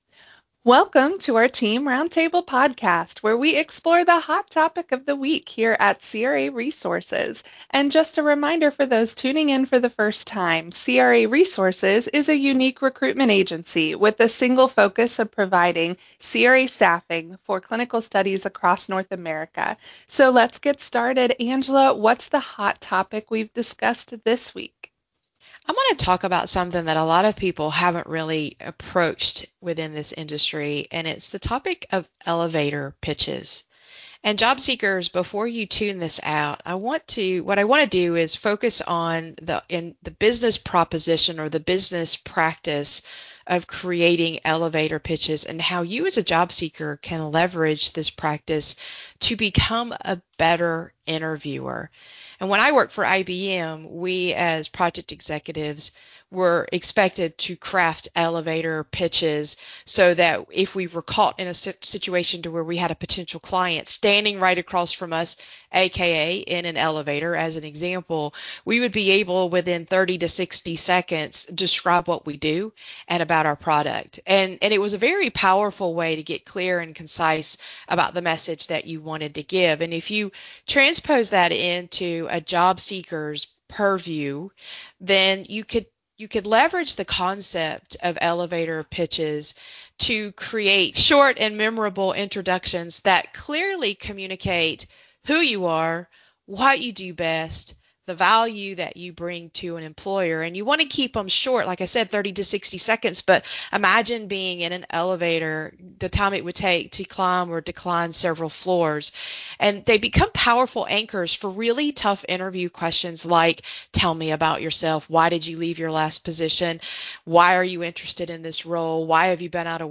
Roundtable: Use an Elevator Pitch to Ace Your Interview - craresources